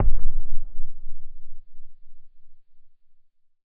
explosion_far_distant_02.wav